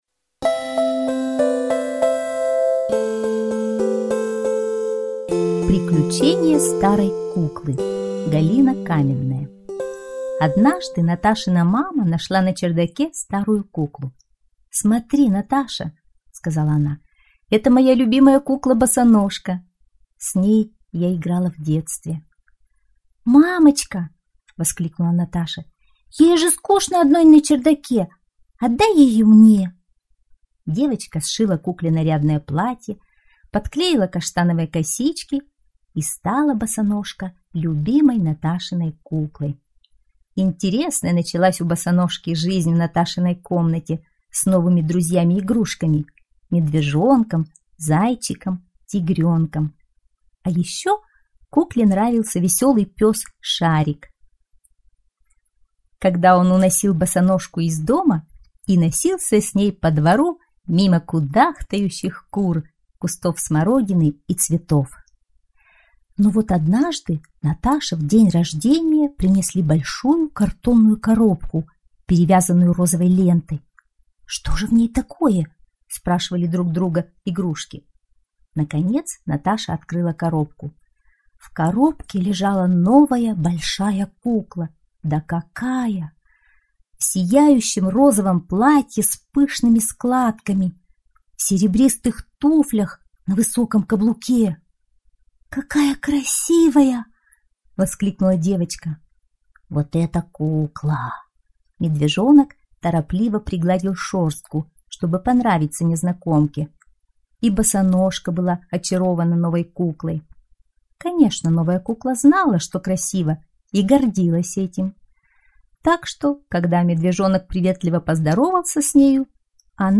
Приключения старой куклы - аудиосказка Каменной - слушать онлайн